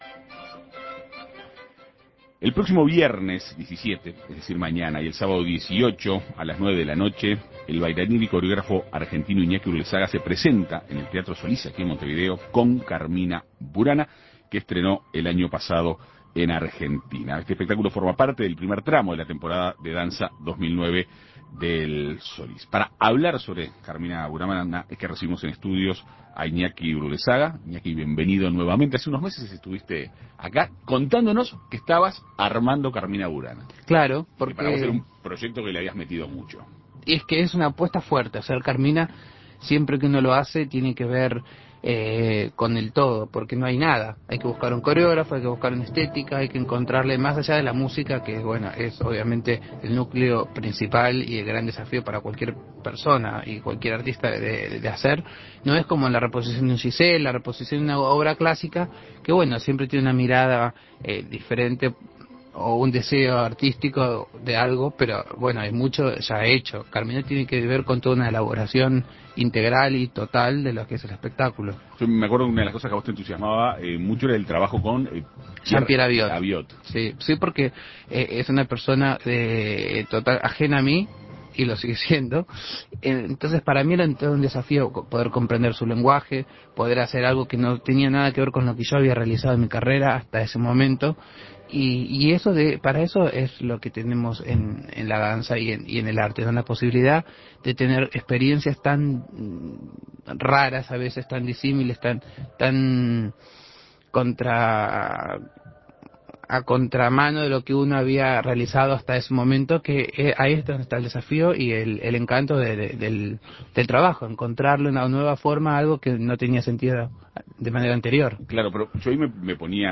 Para conocer detalles del proyecto, En Perspectiva Segunda Mañana dialogó con el artista argentino.